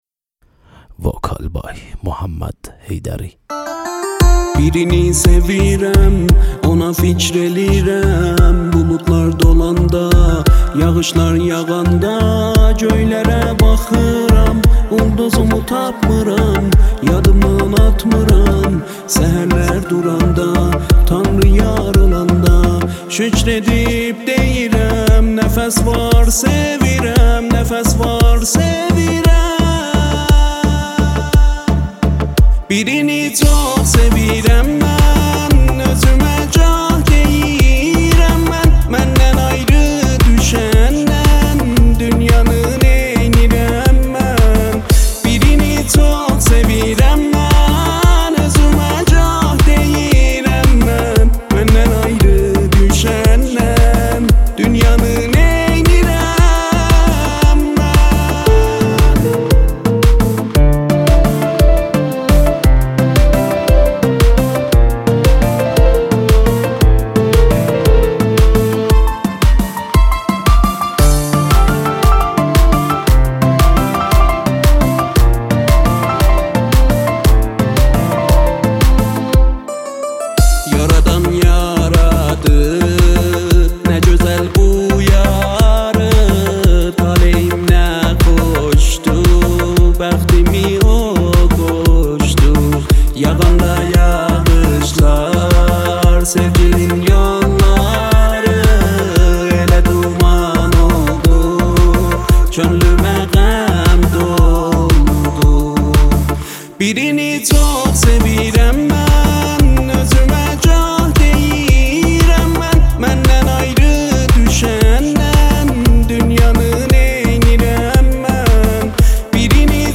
بخش دانلود آهنگ ترکی آرشیو